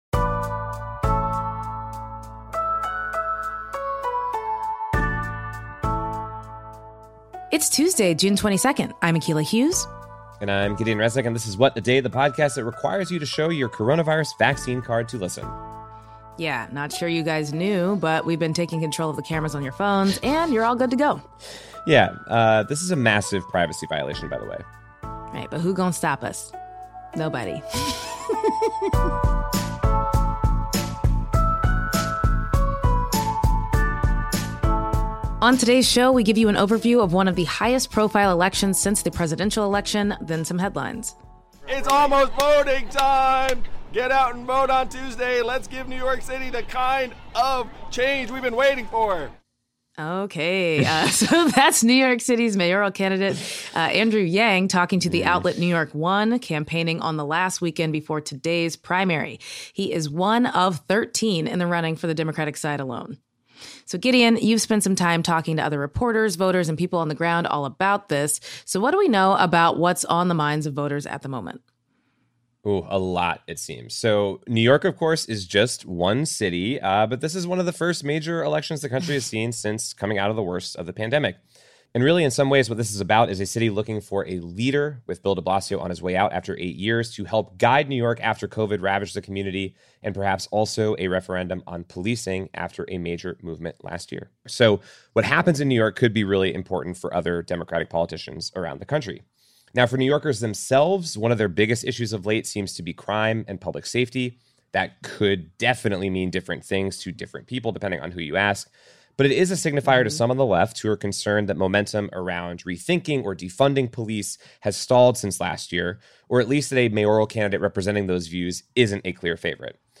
and we interviewed voters to get their perspective, too.